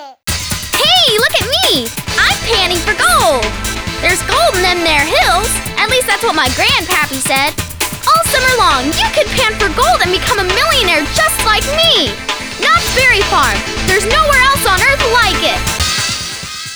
Voice Over Samples